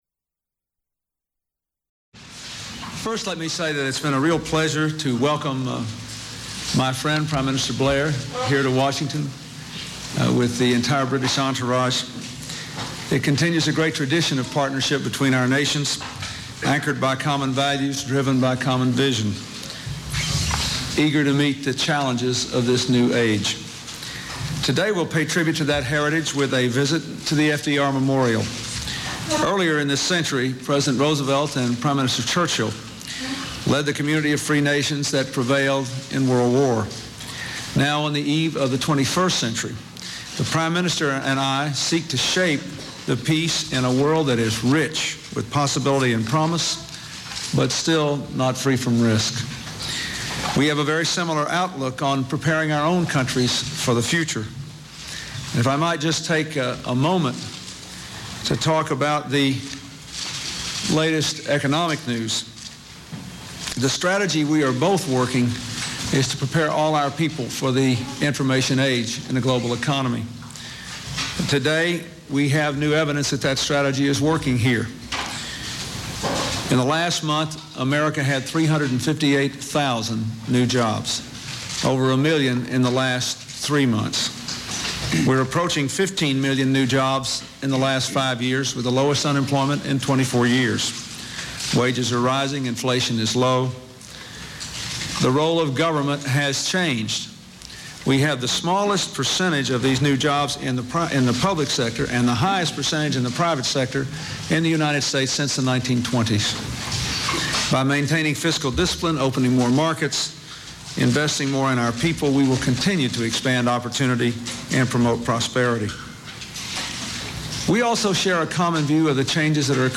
Joint confernce by British Prime Minister Tony Blair and U.S. President Bill Clinton on weapon inspection in Iraq